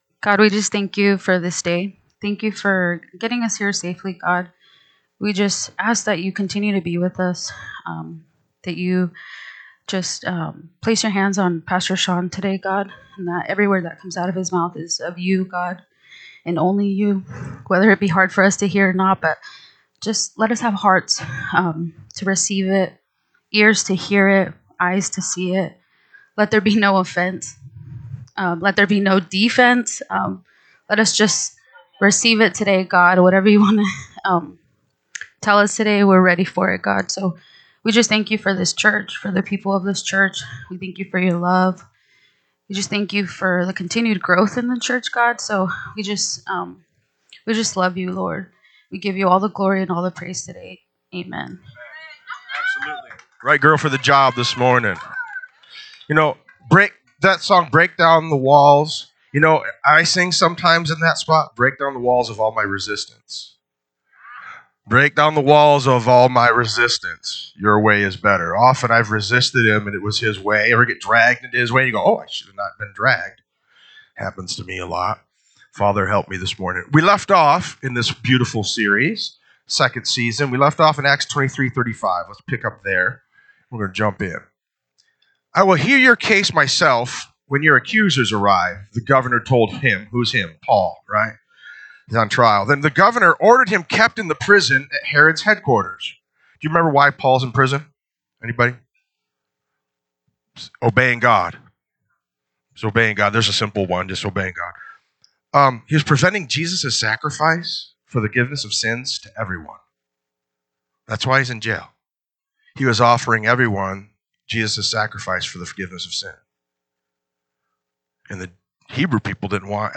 NSCF Sermons Online Acts of the Apostles - Chapter 24 Feb 17 2026 | 00:43:29 1x 00:00 / 00:43:29 Subscribe Share RSS Feed Share Link Embed